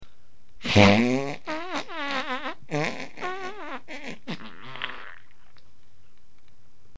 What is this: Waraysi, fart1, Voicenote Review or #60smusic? fart1